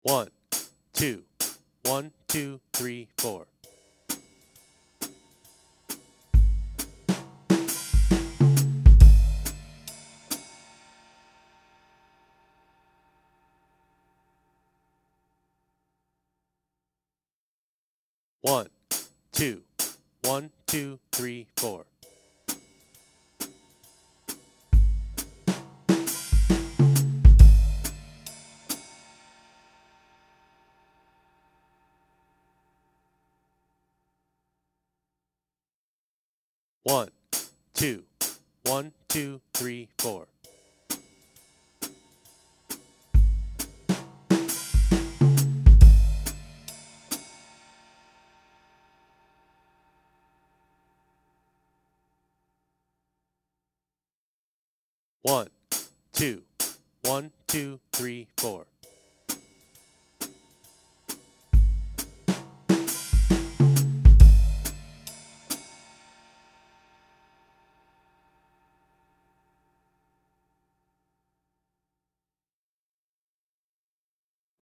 Jazz Fill Tanscription
The Fill: